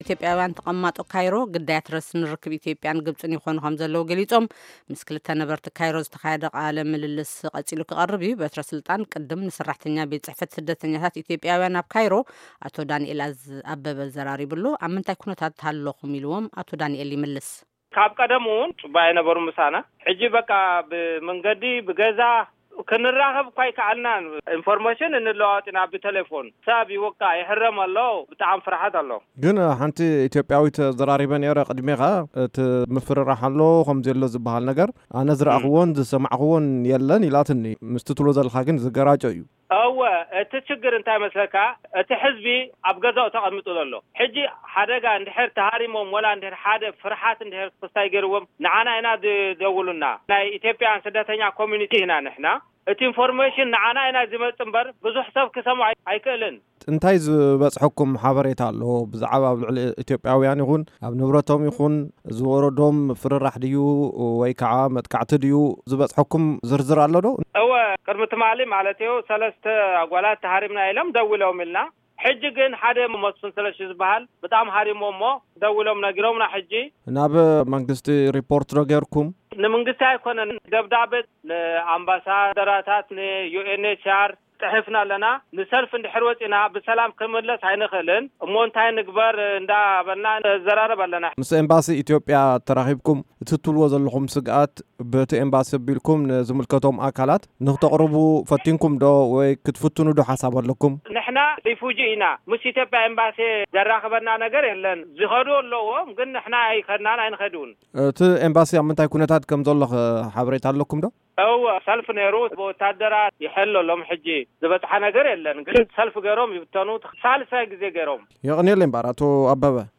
ኢትዮዽያውያን ተቀማጦ ግብፂ፣ግዳያት ረስኒ ርክብ ኢትዮዽያን ግብፅን ይኾኑ ከምዘለው ገሊፆም።ዛጊድ‘ኳ ሰለስተ ደቂ-ኣነስትዮ መጥቃዕቲ ከምዘጓነፈን ተፈሊጡ‘ሎ። ብዝተኣሳሰረ ዜና፣ኣብ ካይሮ ዘሎ ኤምባሲ ኢትዮዽያ ብሰለስተ ታንክታት ይሕሎ ከምዘሎ ኣብ‘ታ ከተማ ዝርከብ ቤት ፅሕፈት ቪኦኤ ዝሃቦ ሓበሬታ ይሕብር። ብዛዕባ ኩነታት ኢትዮዽያውያን ኣብ ግብፂ ምስ ክልተ ኢትዮዽያውያን ዝተካየደ ቃለ-ምልልስ‘ሎ። ኣዳምጹ